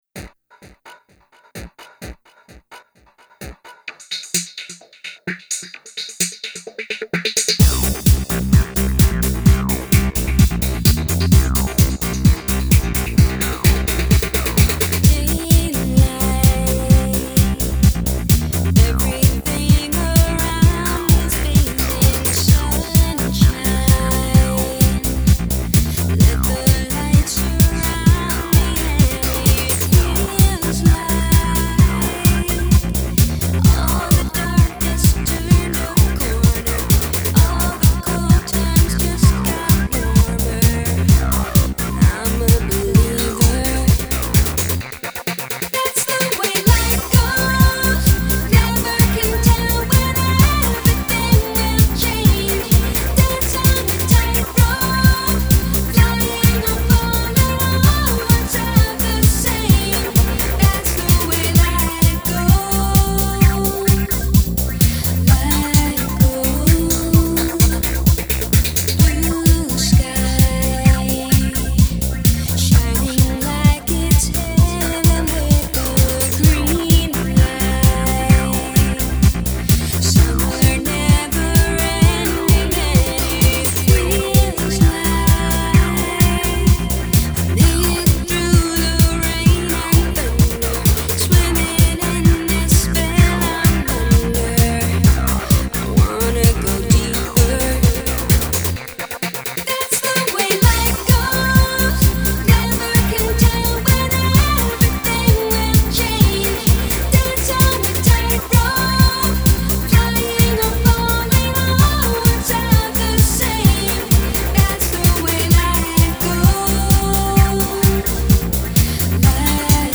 Uptempo, inspiring, Euro Dance Song
Dance Music , Female Vocal